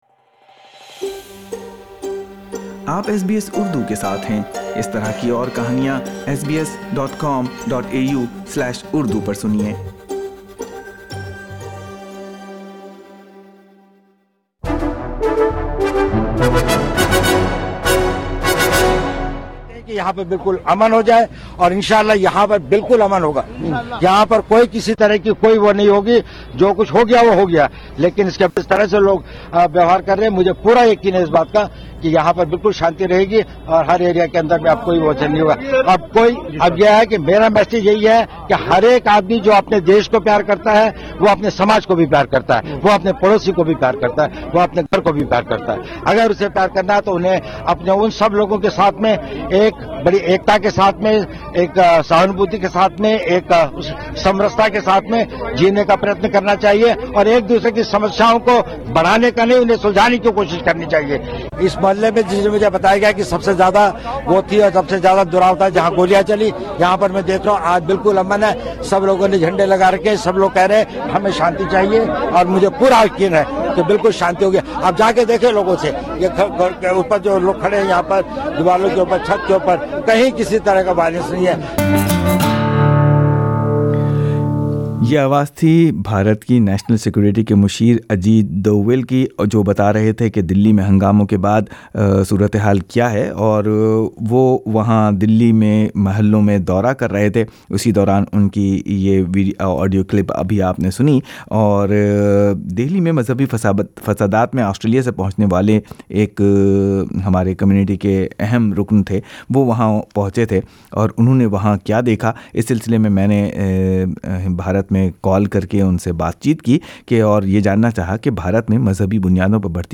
بھارتی دارالحکومت نئی دلی کے حالیہ فسادات میں درجنوں افراد ہلاک، کئی زخمی ہوئے۔ ایس بی ایس اردو نے آسٹریلین کمیونٹی سے تعلق رکھنے والے سماجی کارکن اور کمونیٹی ممبر سے بات کی جو ان فسادات کے دوران آسٹریلیا سے نئی دلّی پہنچے تھے۔